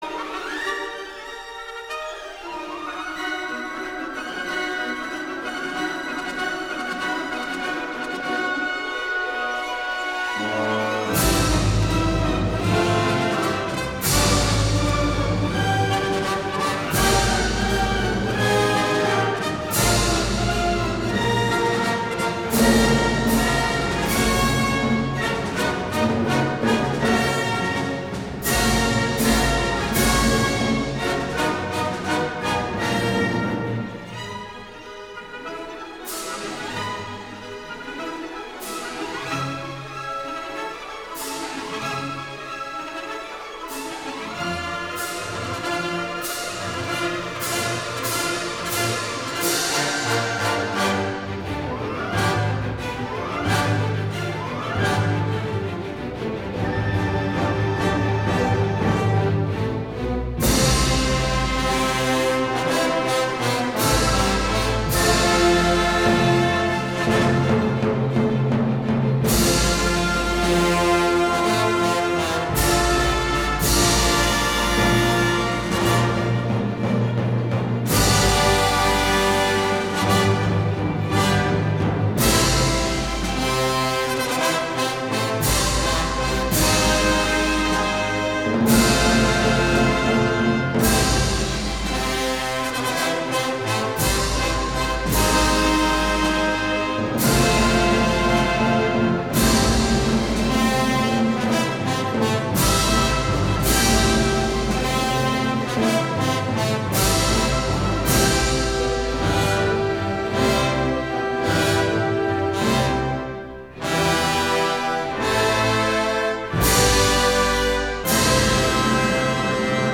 录音： 1960年7月12~15日,Walthamstow Town Hall,London(伦敦瓦瑟斯多市政音乐厅)